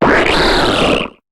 Cri d'Amphinobi dans Pokémon HOME.